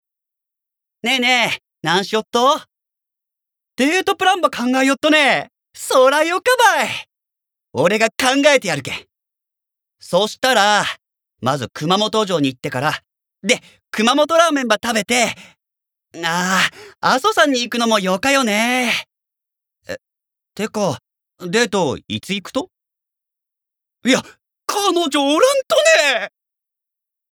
ボイスサンプル
セリフ６